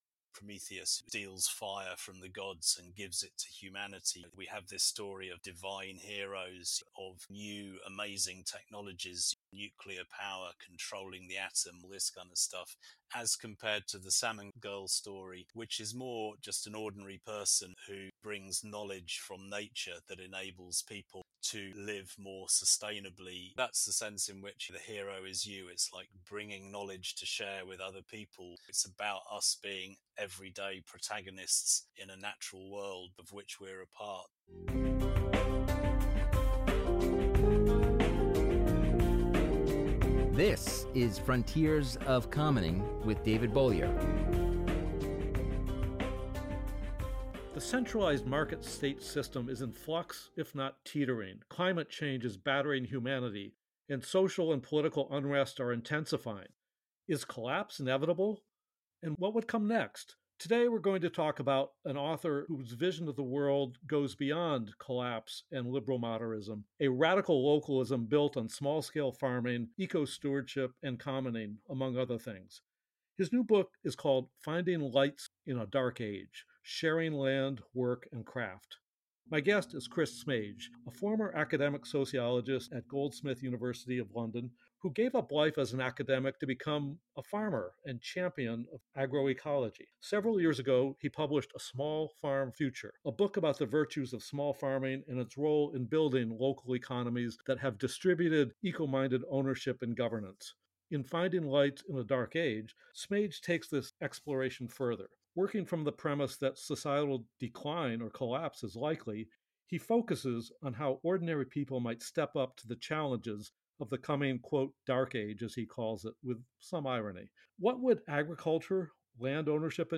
A monthly conversation with creative activists pioneering new forms of commoning.